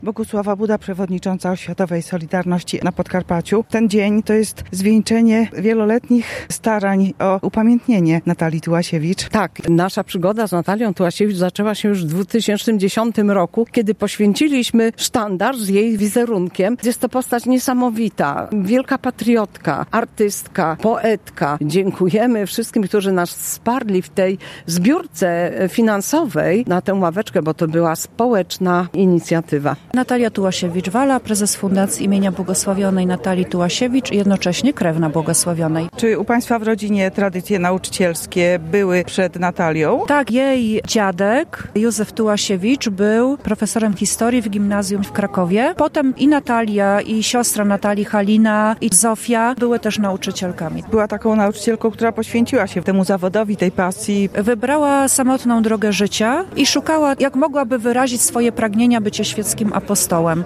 Relacje reporterskie